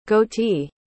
A pronúncia correta é /ˈɡoʊ.ti/, parecido com “gôuti” em português.